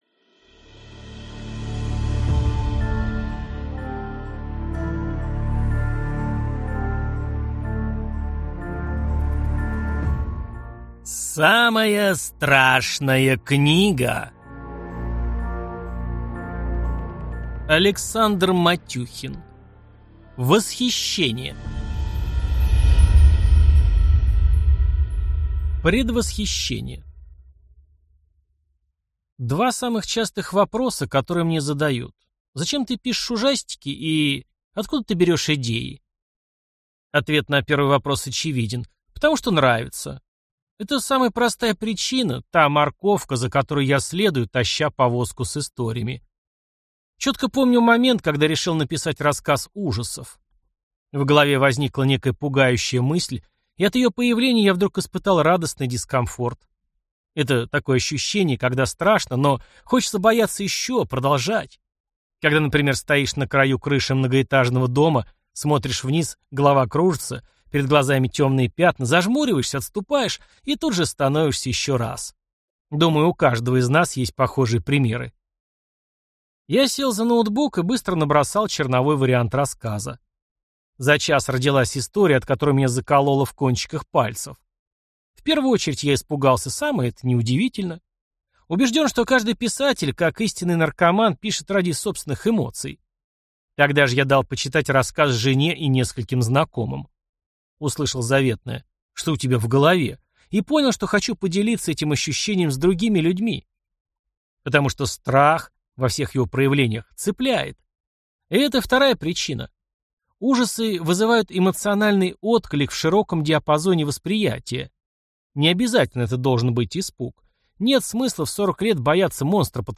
Аудиокнига Восхищение | Библиотека аудиокниг